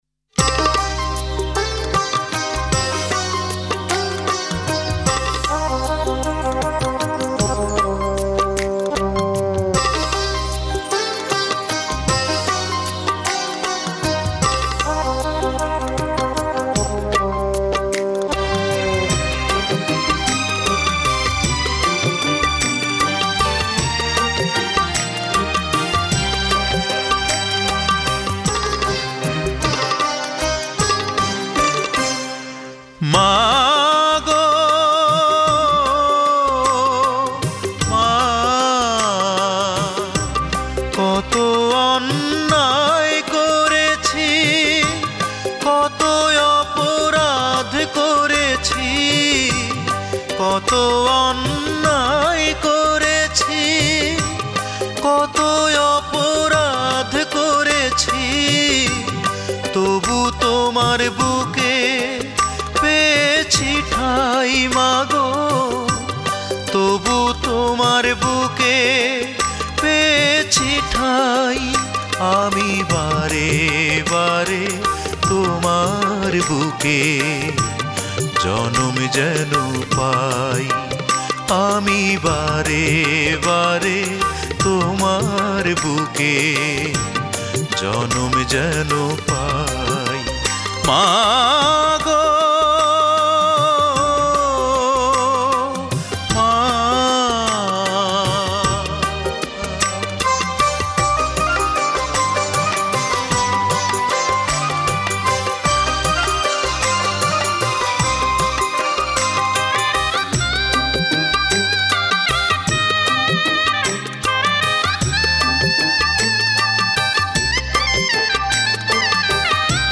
Genre Adhunik Bangla